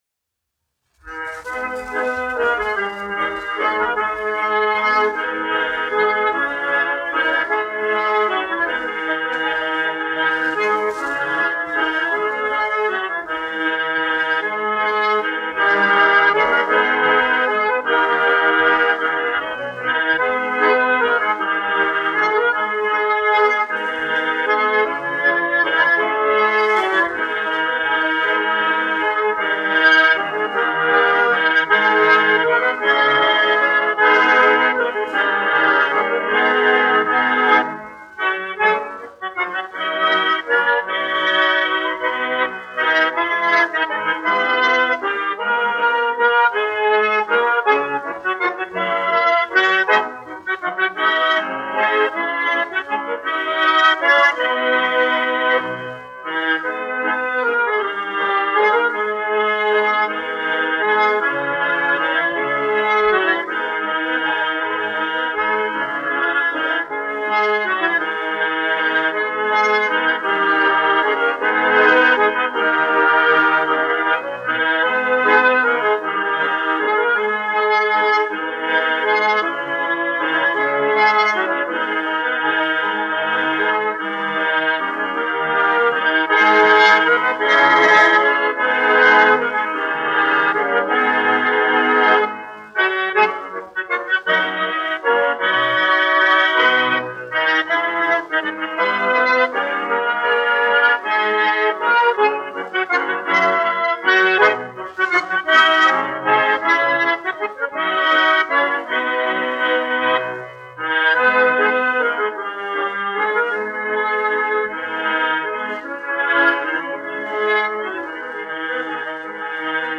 1 skpl. : analogs, 78 apgr/min, mono ; 25 cm
Ermoņikas
Skaņuplate